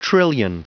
Prononciation du mot trillion en anglais (fichier audio)
Prononciation du mot : trillion